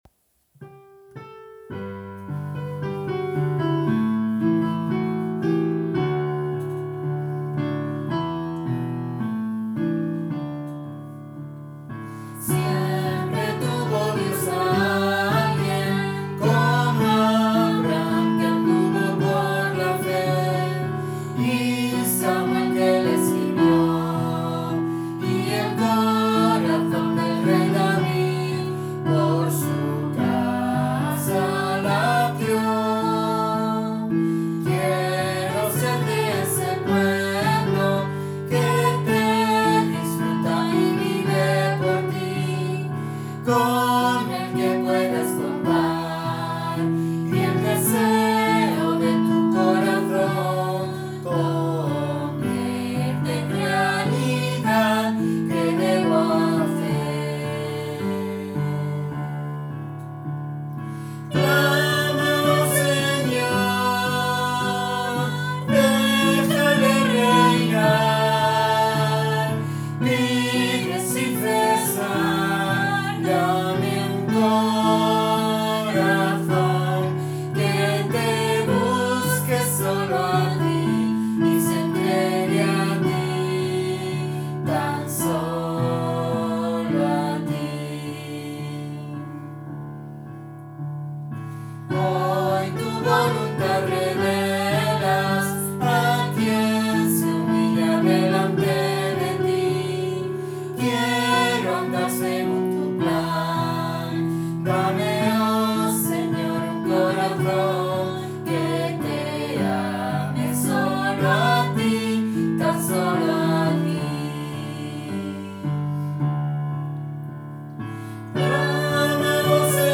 Mel. alemán